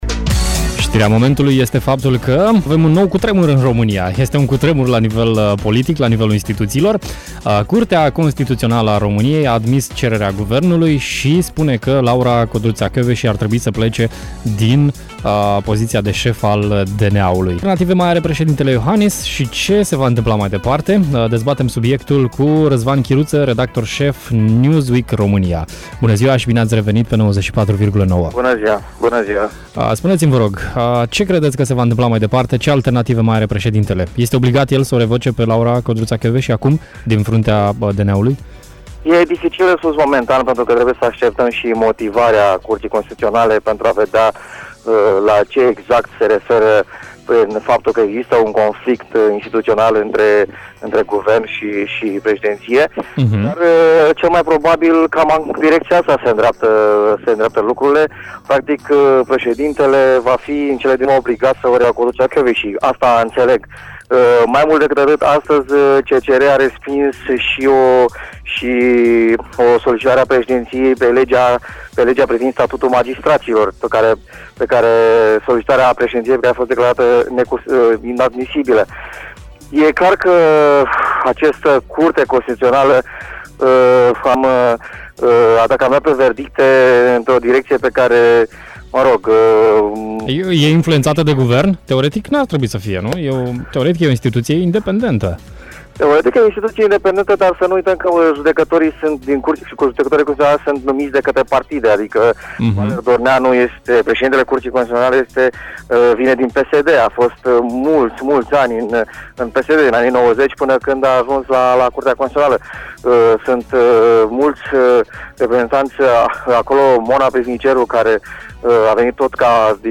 a fost în direct la Radio Hit unde a făcut o analiză a acestui moment: